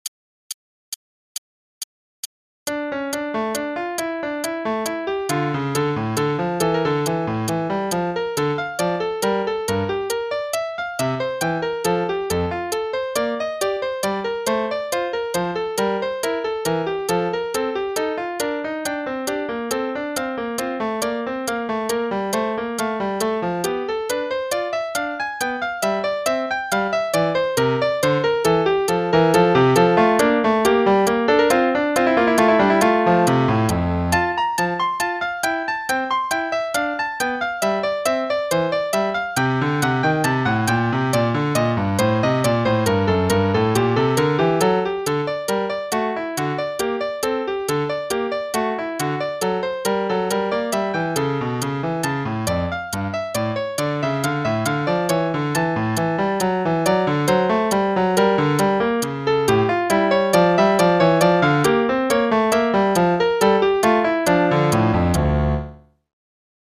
Play-along: Prelude in D minor (en=120)